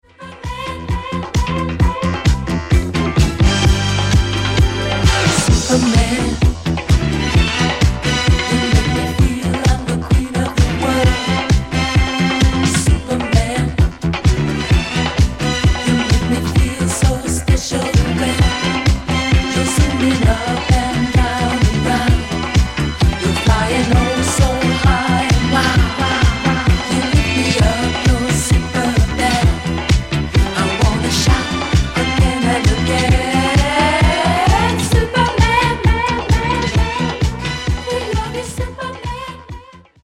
12''Mix Extended
Disco Funk e Dance Clssics degli anni 70 e 80.